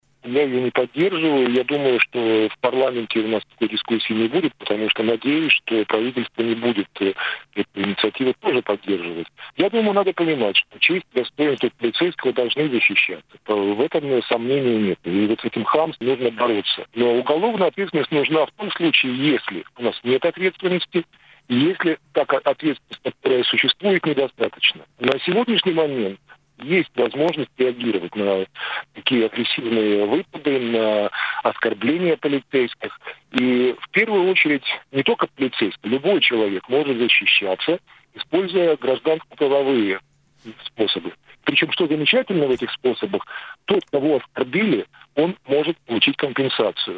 На радио Baltkom сегодня обсуждали сразу несколько тем — надо ли ужесточать наказания за оскорбление полицейских, надо ли вводить запрет на салюты и стоит ли  в Латвии ограничивать торговлю оружием.